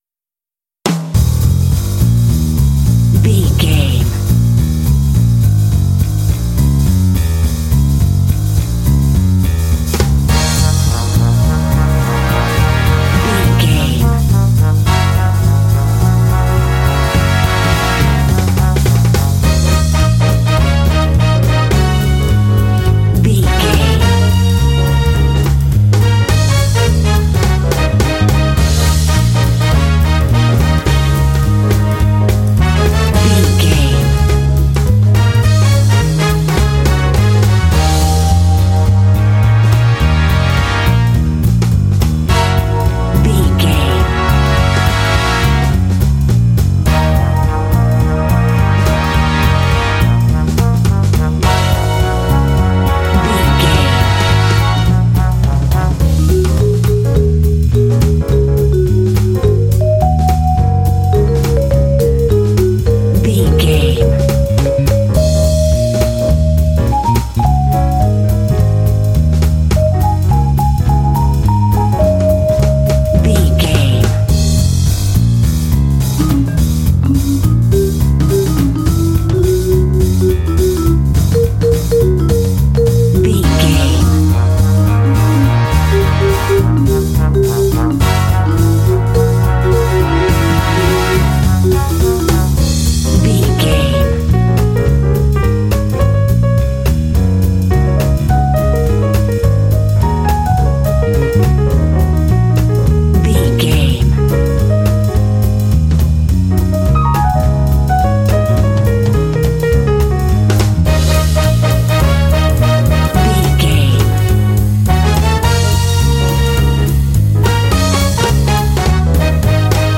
Aeolian/Minor
E♭
energetic
groovy
lively
bass guitar
piano
drums
brass
jazz
big band